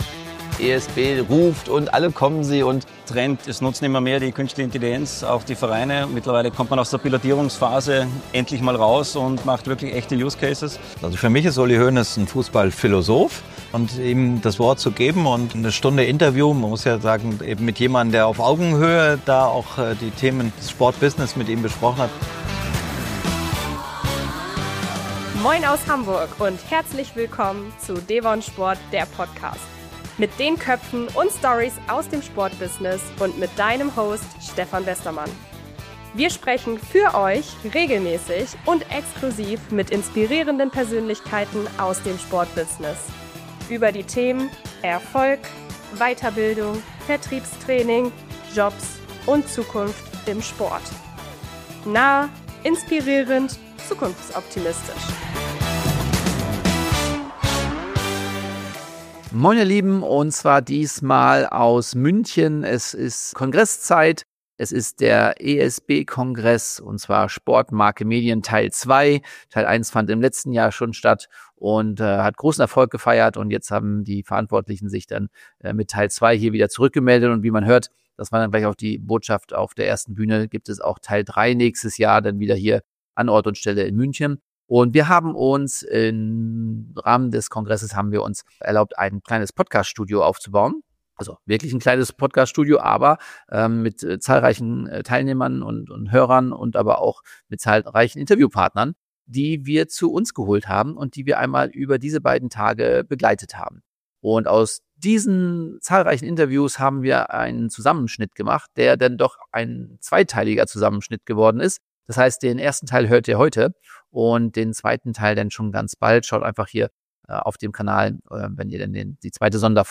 Von KI-gestützter Live-Übersetzung über zentrale LED-Banden-Steuerung bis zur Basketball-WM 2025: Der Sport ist im Wandel. In dieser Folge nehmen wir dich mit auf den Sport Marke Medien Kongress in München – und zeigen dir, wo Tradition auf Innovation trifft und welche digitalen Trends den Sport von...